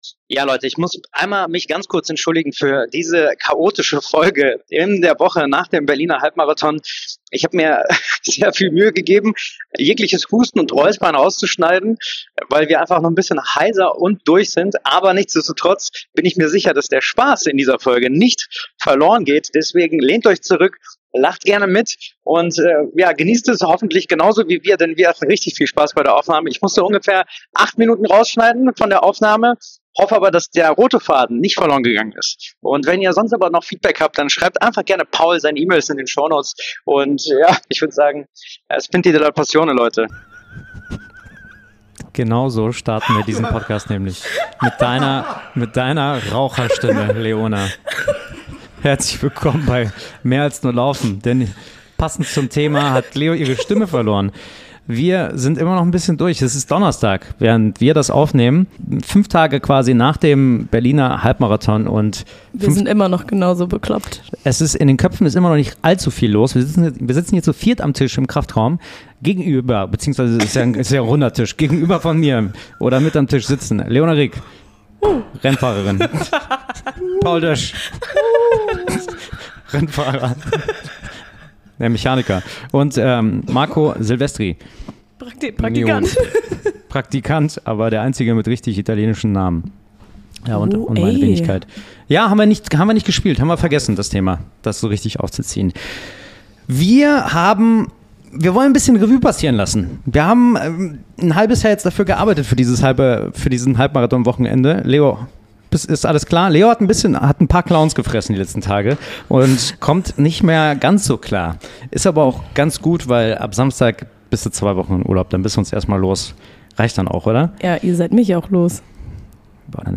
Mit ordentlich viel Husten, rauer Stimme und jeder Menge Lachanfällen sprechen wir über Vorbereitungen, Highlights, chaotische Boxenstopps und Marmeladenglas-Momente.